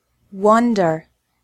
The WON of wonder is like the words “won” or “one.”